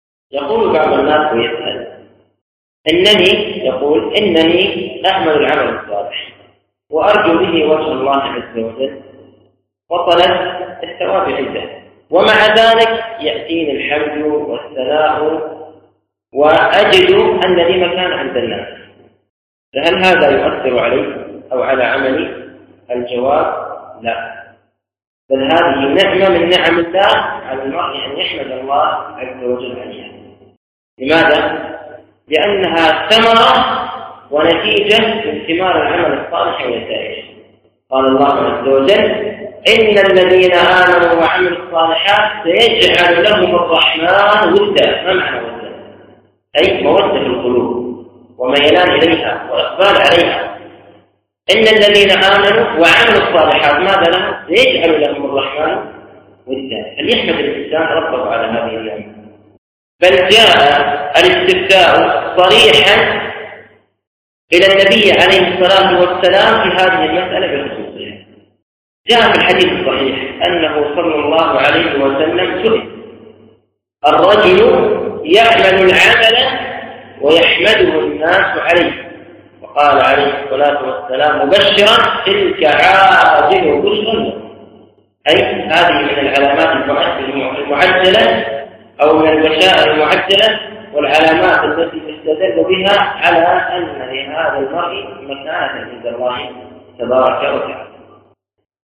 التنسيق: MP3 Mono 48kHz 56Kbps (VBR)